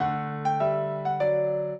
piano
minuet0-3.wav